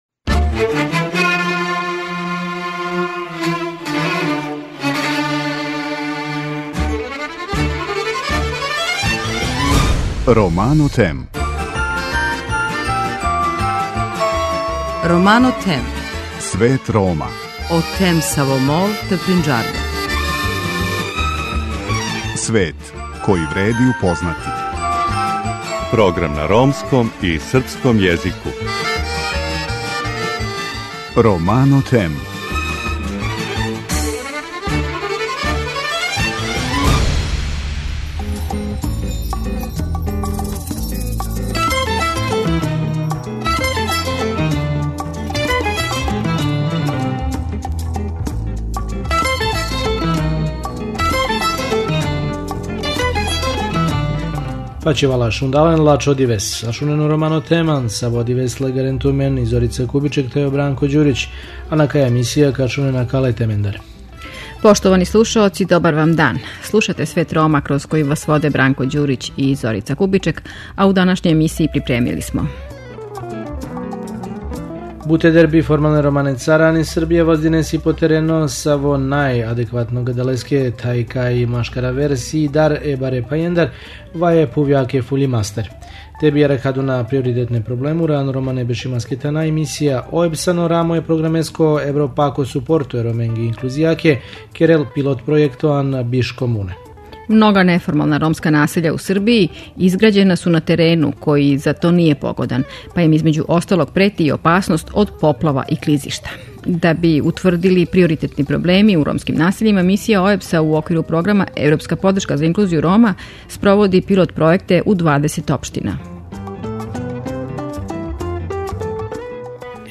преузми : 19.09 MB Romano Them Autor: Ромска редакција Емисија свакодневно доноси најважније вести из земље и света на ромском и српском језику. Бави се темама из живота Рома, приказујући напоре и мере које се предузимају за еманципацију и интеграцију ове, највеће европске мањинске заједнице.